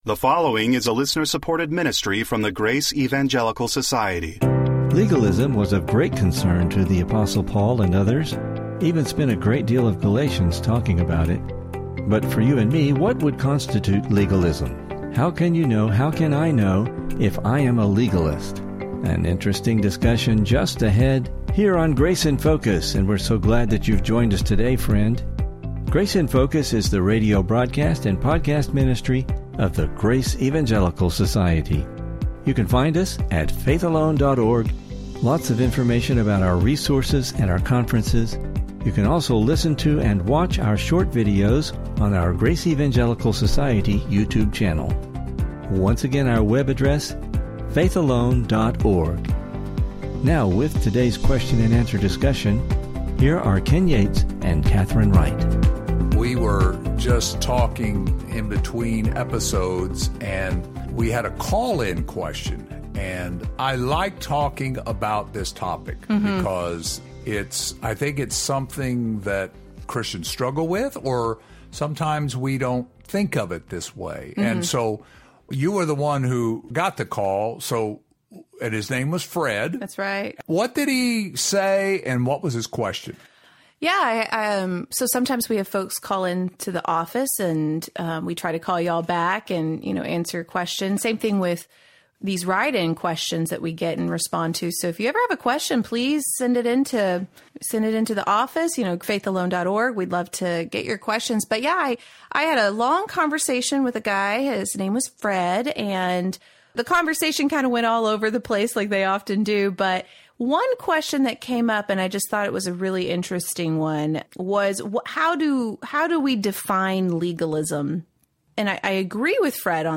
Please listen for an interesting discussion and lessons related to this topic.